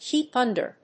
音節keep under